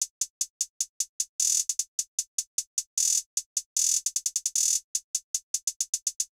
Closed Hats
Hihat1.wav